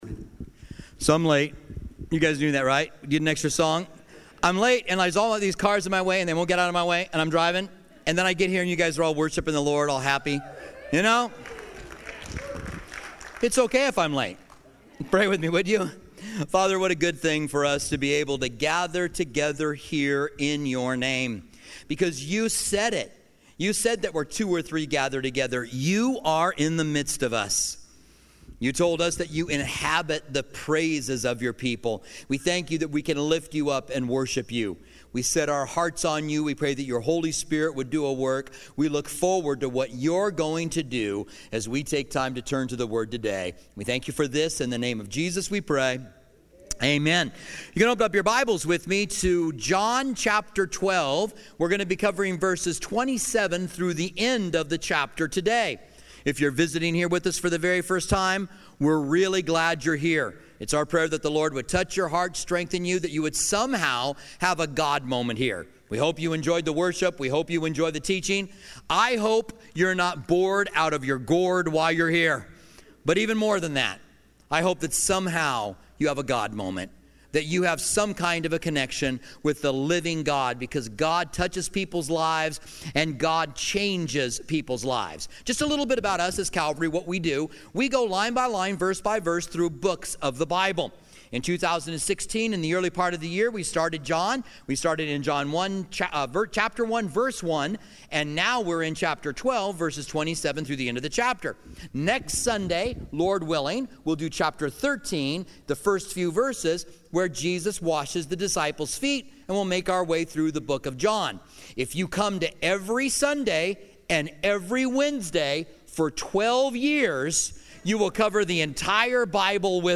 A Final Public Address Pt. 2 - John 12:27-50 - Calvary Tucson Church